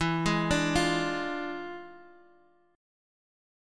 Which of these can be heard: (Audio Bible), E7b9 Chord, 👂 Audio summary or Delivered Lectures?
E7b9 Chord